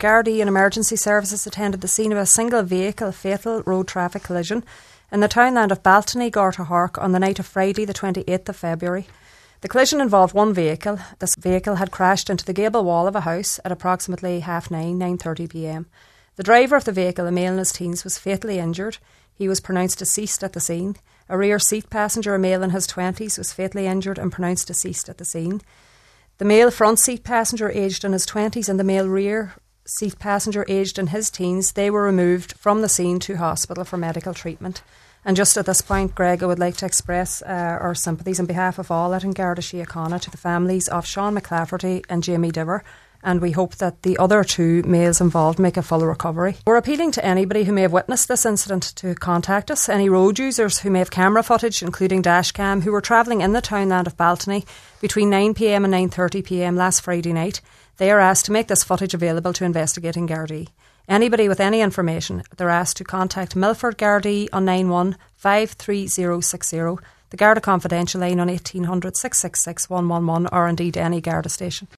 The appeal was made on today’s Nine til Noon Show